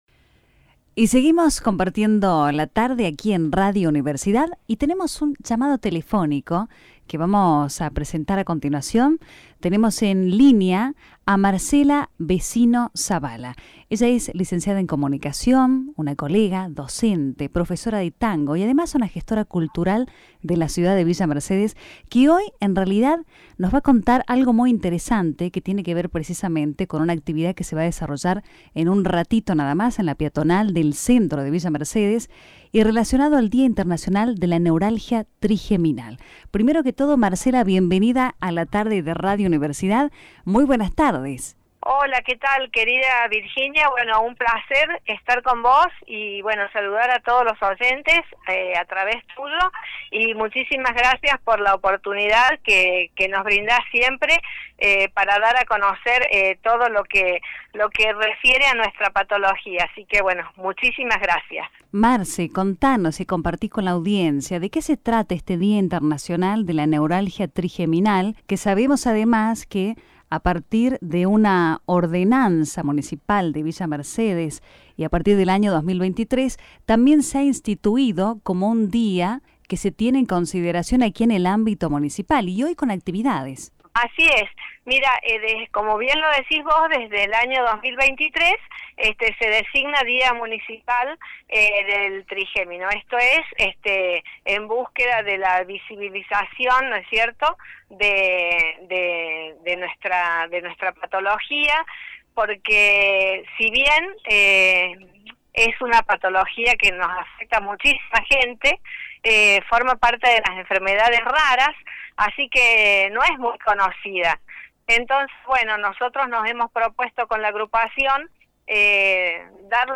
Entrevista realizada en el programa radial “La Quilla”